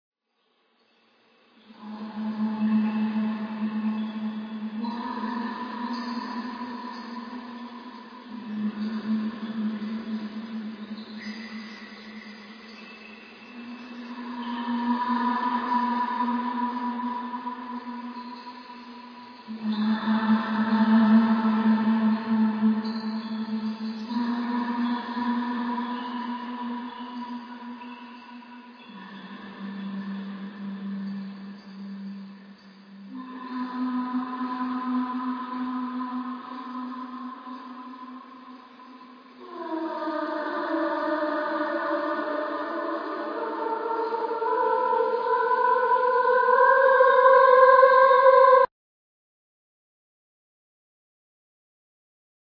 Scary Young Girl Ghost Lost in Cave
Scary young girl ghost voice lost in cave setting with water dripping. Great sound effect for horror film.
32kbps-CG-Freaky-Girl-Vox-w-Water.mp3